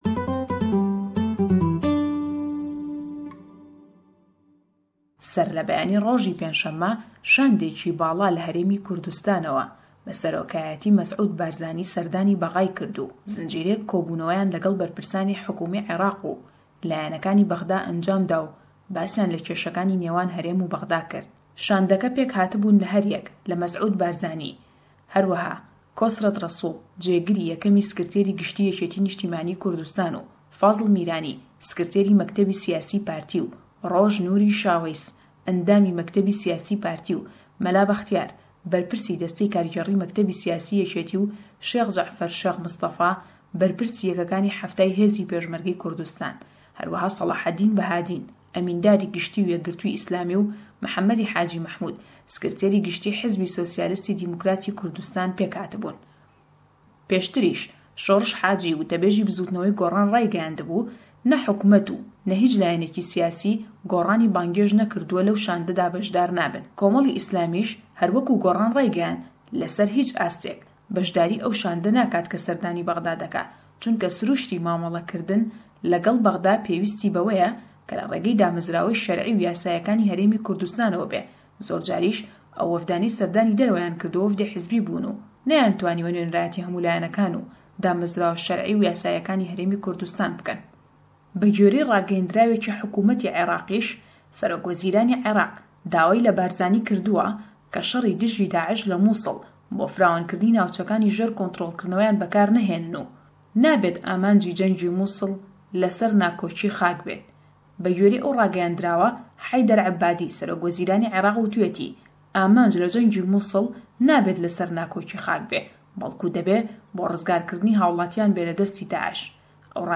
Em raportey peyamnêrman